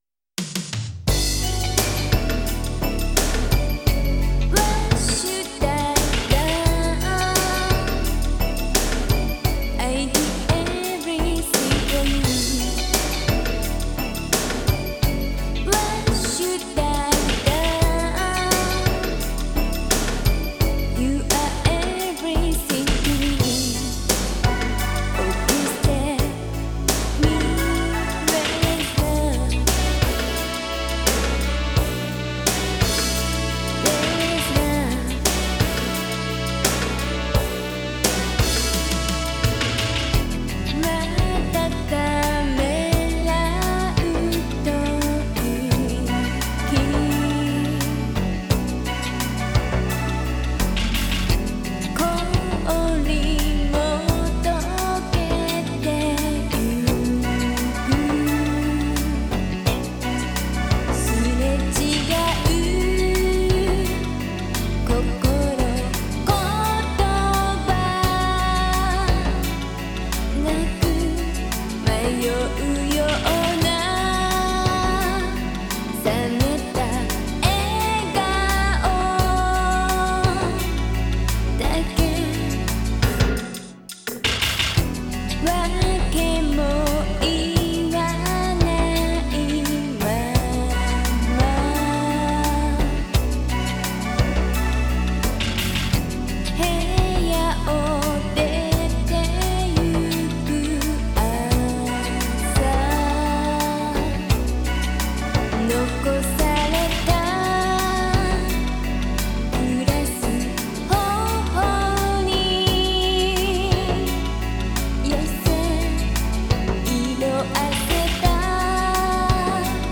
ジャンル(スタイル) JAPANESE POP / R&B